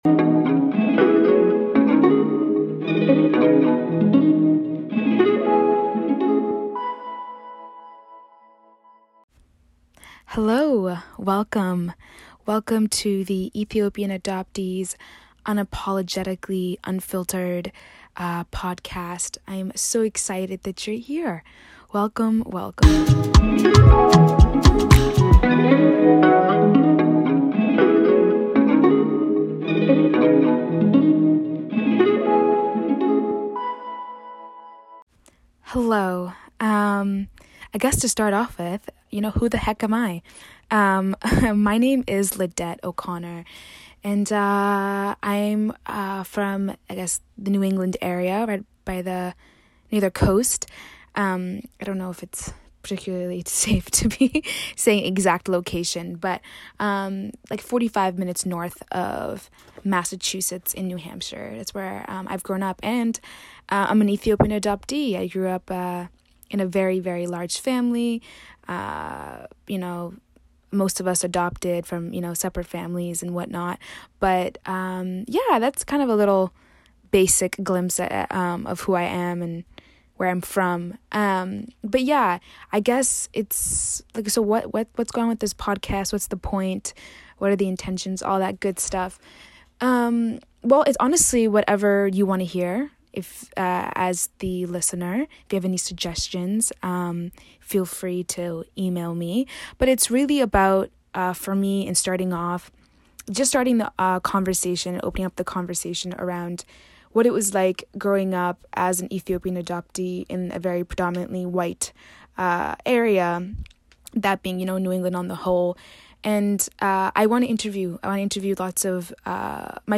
Although this episode consists only of me talking to myself (haha), most of the future episodes will include a guest!